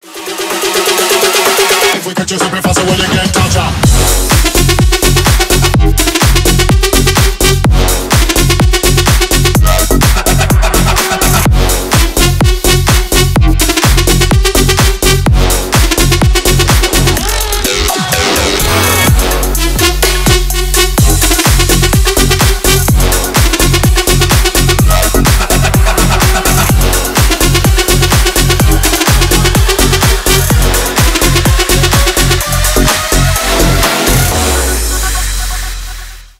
Танцевальные # клубные # громкие